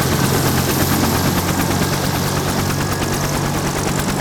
propellersstart.wav